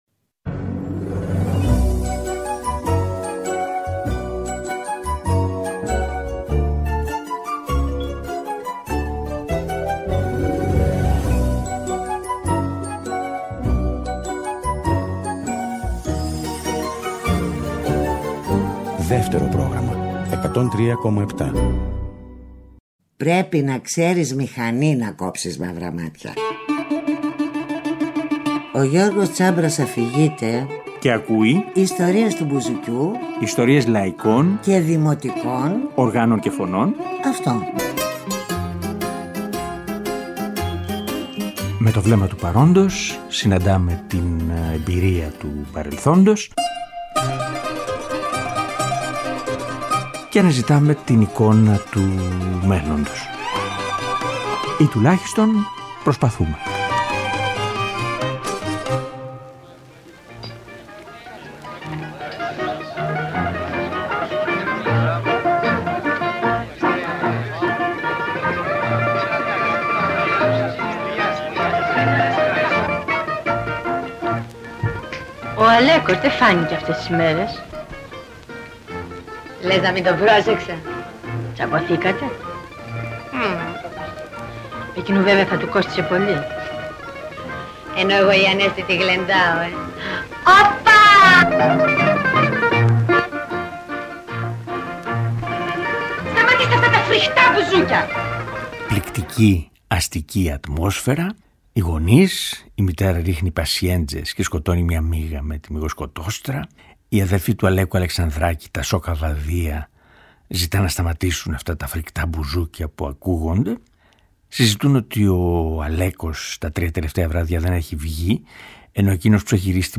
Φαντασία για μπουζούκι και πιάνο με τον Βασίλη Τσιτσάνη και τον Μάνο Χατζιδάκι